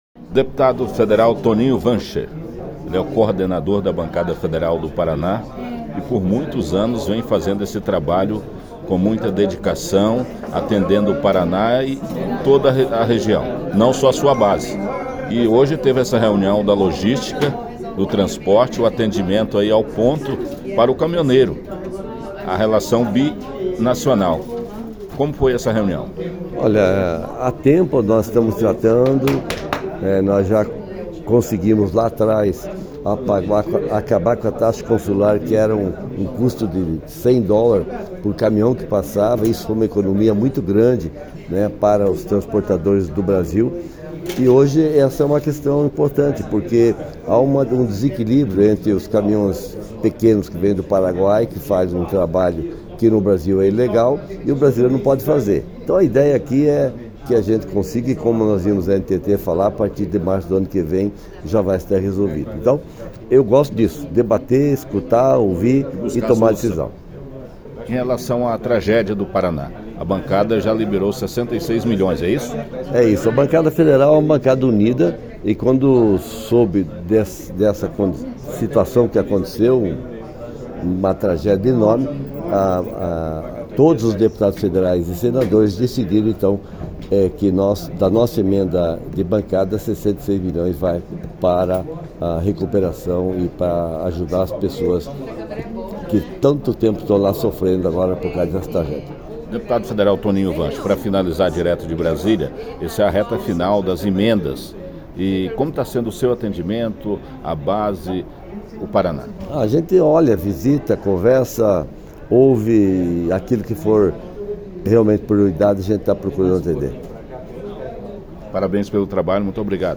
Toninho Wandscheer, líder da bancada do Paraná, conversou com nosso correspondente em Brasília